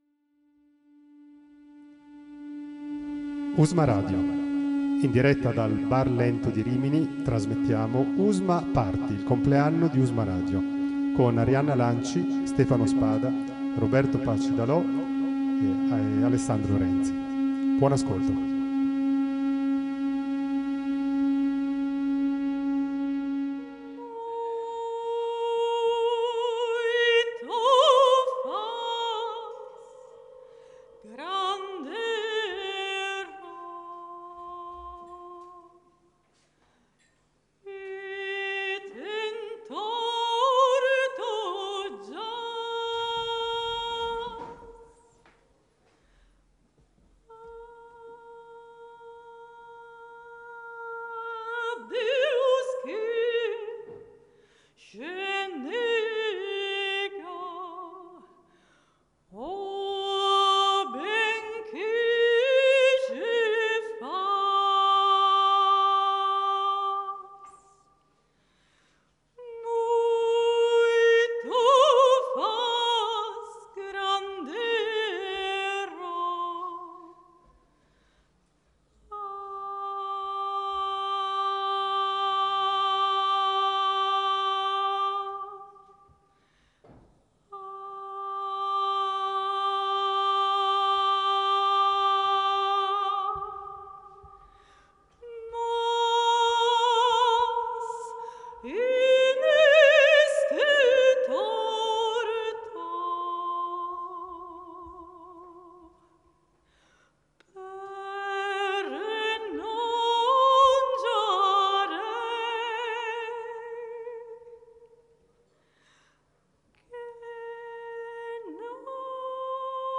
LIVE
Presso Bar Lento – Via Aurelio Bertola, 52 Rimini